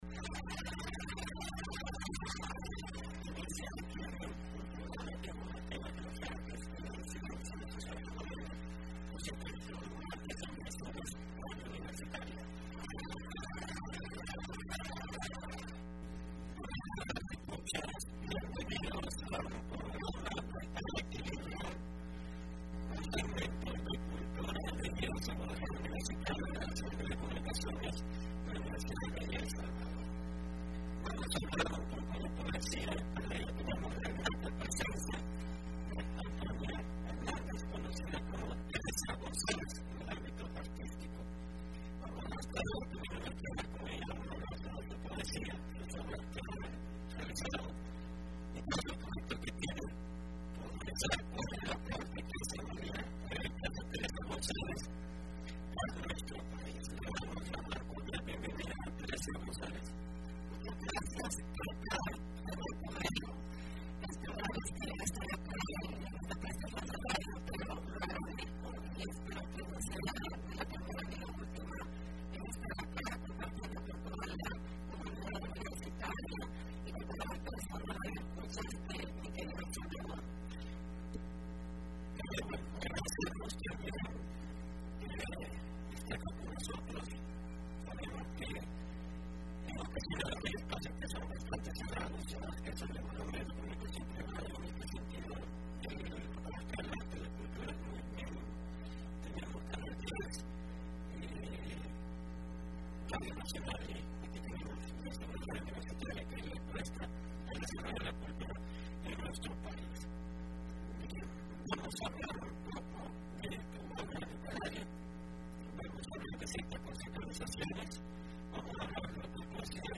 Entrevista programa Aequilibrium (03-03-15)